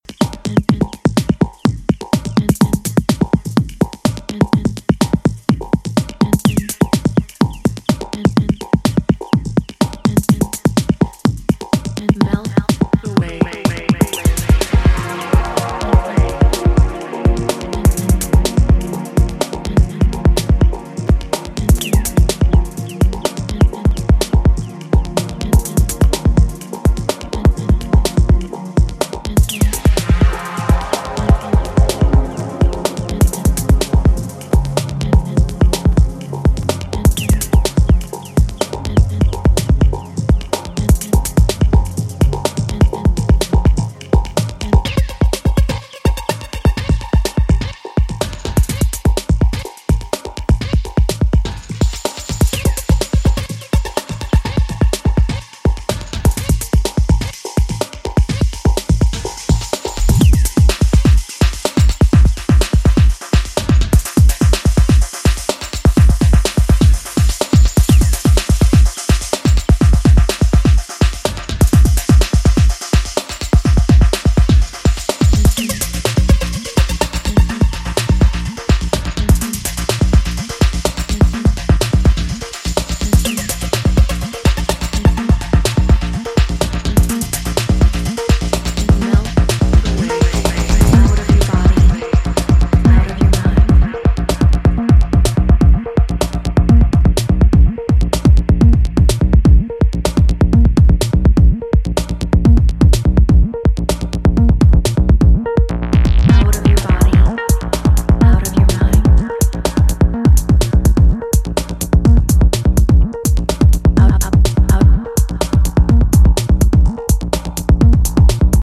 expect wiggy steppers destined for the forest.